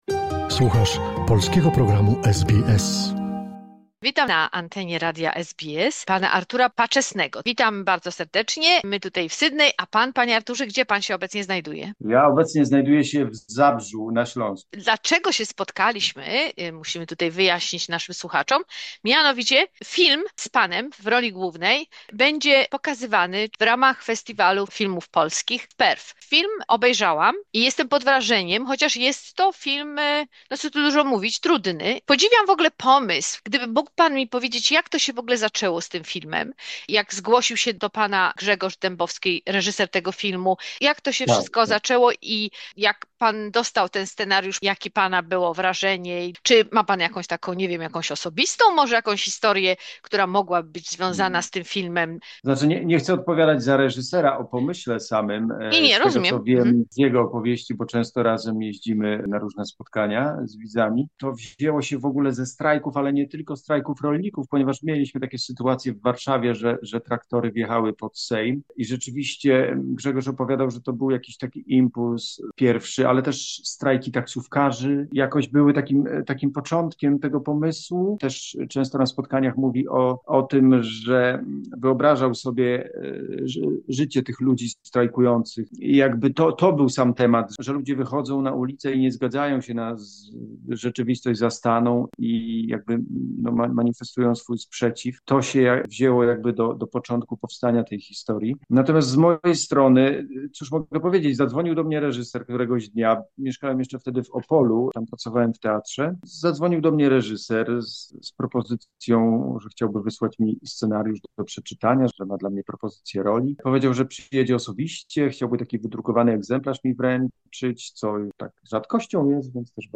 Rozmowa